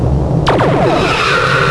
Fire Photon Torpedoes!